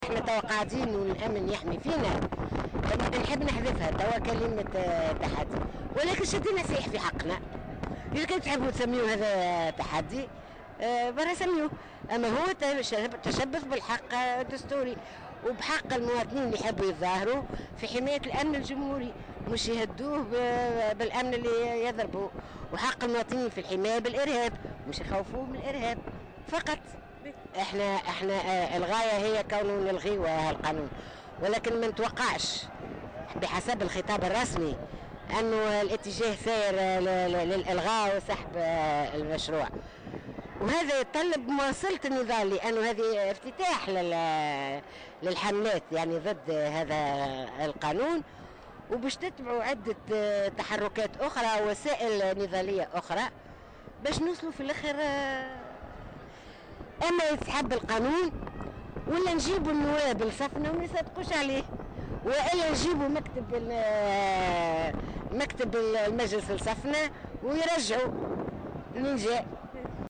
La militante Naziha Rjiba (Om Zied) a indiqué ce samedi 12 septembre 2015 dans une déclaration accordée à Jawhara FM en marge de la marche contre la réconciliation, que cette manifestation n’a pas été organisée dans le but de défier le ministère de l’intérieur.